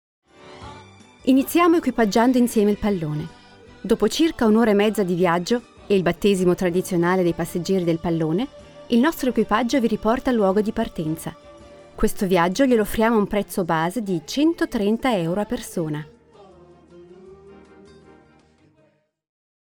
Schauspielerin und Sprecherin, Muttersprache Italienisch, Deutsch mit F od. I Akzent, E und F mit I Akzent, breites Spektrum an Stimmlagen , Gesang.
Sprechprobe: Werbung (Muttersprache):
Native speaker (italian) and actress.Can speak german, french and english with french or italian accent.